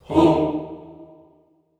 SouthSide Chant (57).WAV